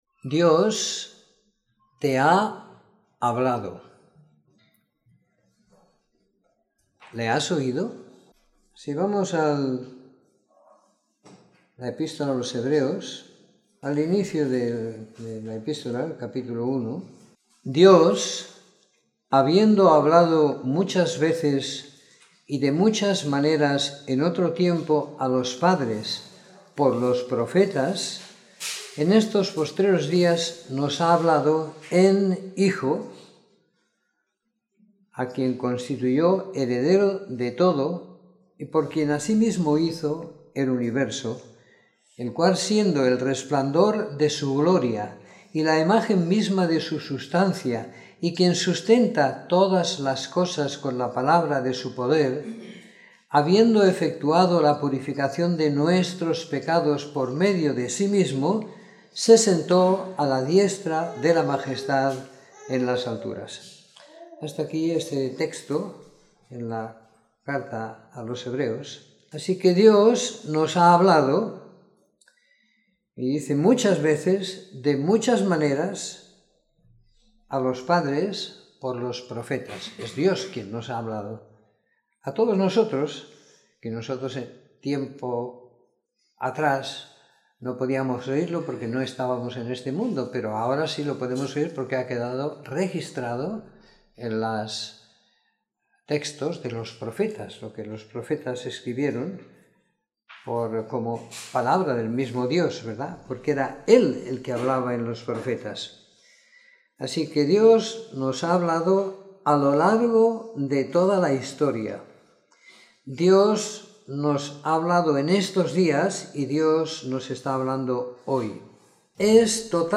Domingo por la Mañana . 29 de Octubre de 2017